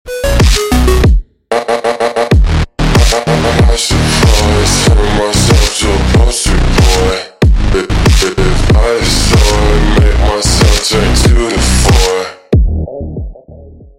Small vs big lego sound effects free download